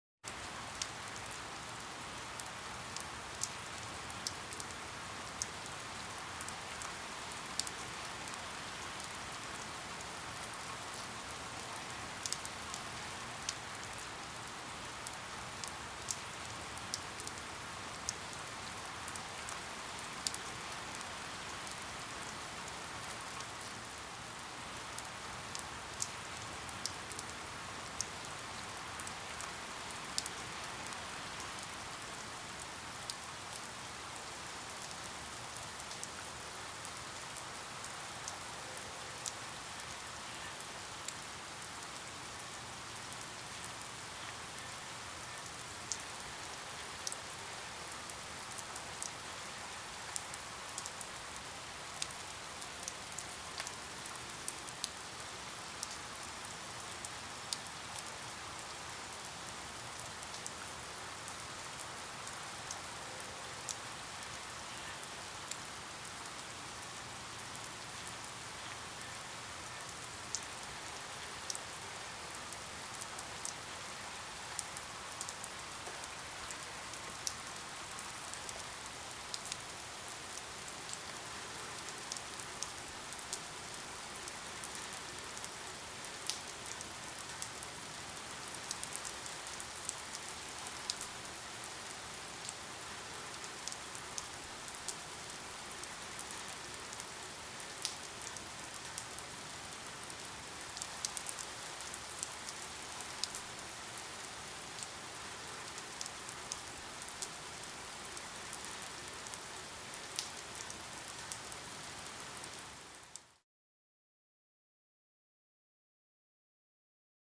Все дожди записаны без грома, это просто дожди, которые можно скачать для звукового оформления любого контента. Качество записей высокое.
Слышны всплески капель, сам дождичек мелкий — 01 мин 57 сек